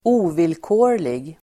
Uttal: [²'o:vilkå:r_lig]